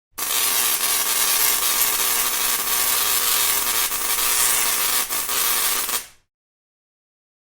Shorted Welder
Shorted Welder.mp3